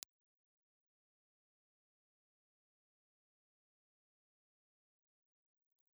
Impulse Response file of the STC 4031 VIE microphone, on axis
STC_4021_VIE-Grill_OnAxis.wav
Impulse response files have been supplied with the microphone positioned horizontally (on axis) and vertically to the source.